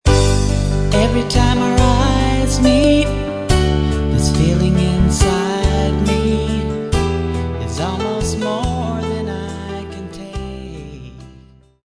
Samples Of Cover Tunes With Vocals